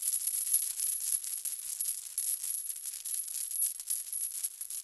CHAIN_Rattle_04_loop_mono.wav